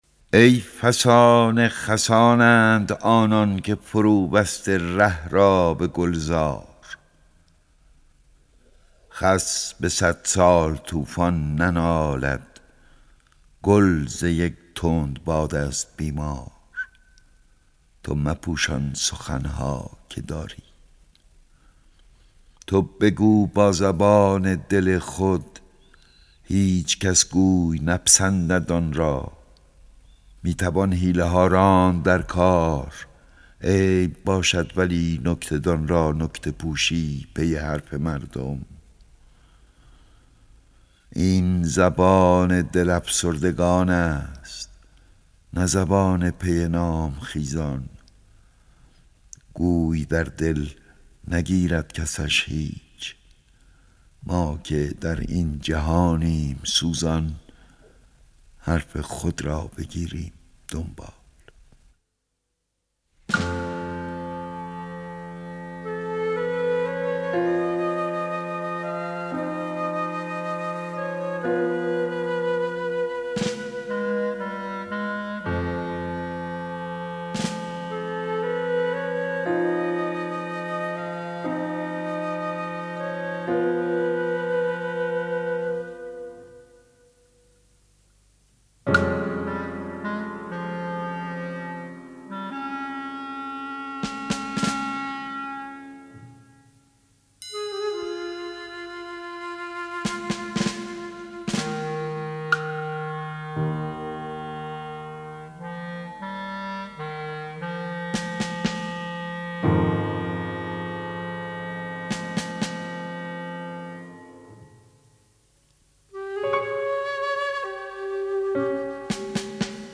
اطلاعات دکلمه
گوینده :   [احمد شاملو]
آهنگساز :   فرهاد فخرالدینی